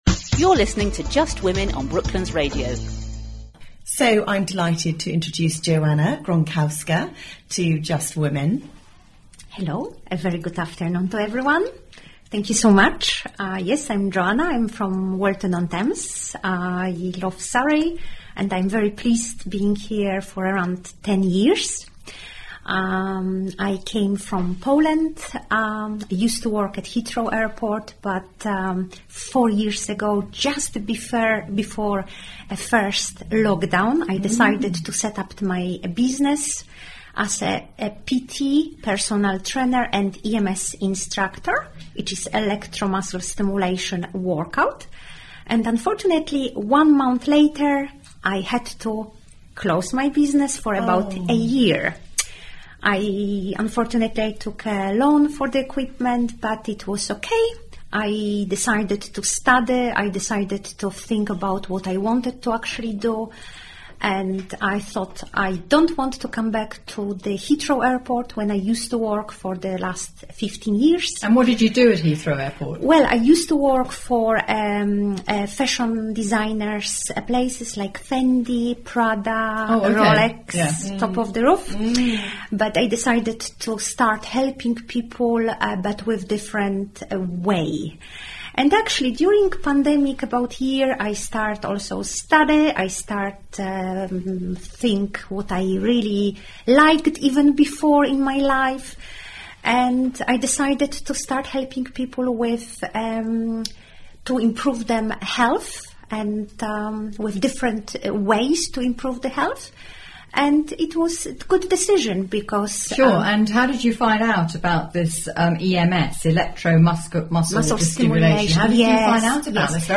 If you missed my first radio broadcast at Brooklands radio at “Just women” you can hear it here.